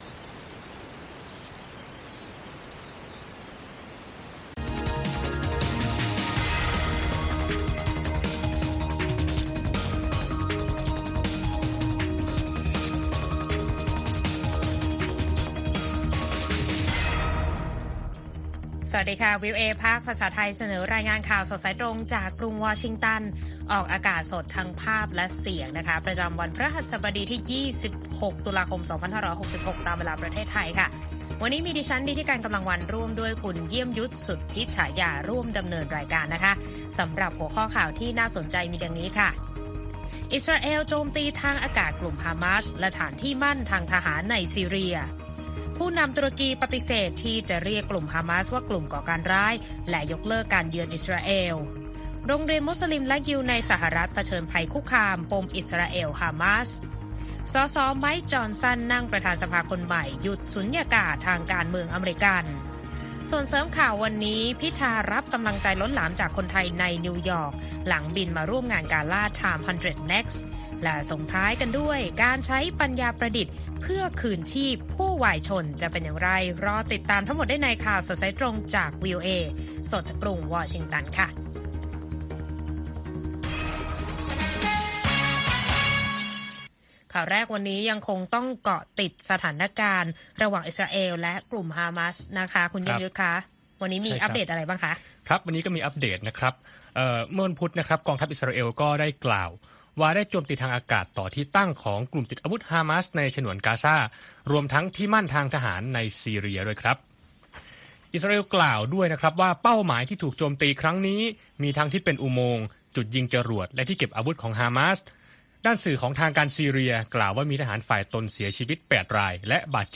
ข่าวสดสายตรงจากวีโอเอไทย 8:30–9:00 น. วันพฤหัสบดีที่ 2 พฤศจิกายน 2566